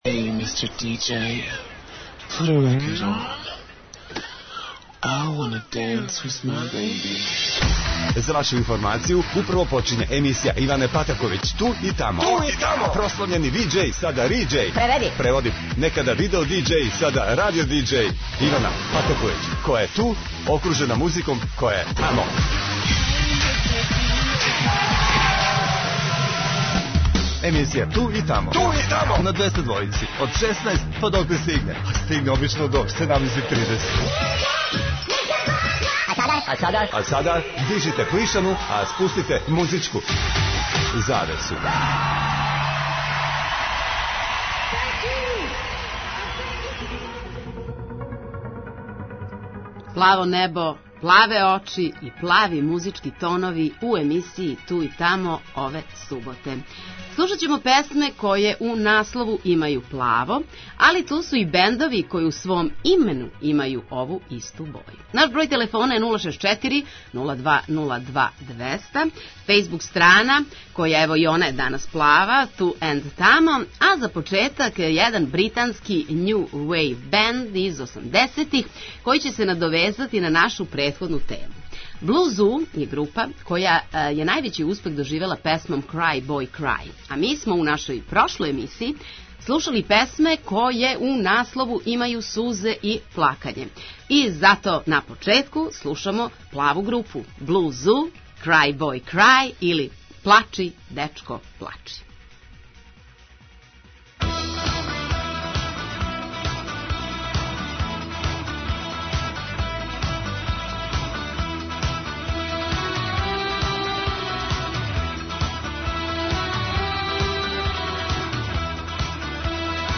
За слушаоце Двестадвојке смо направили музички пакет са песмама које у наслову имају плаво и групама које у имену имају ту исту боју.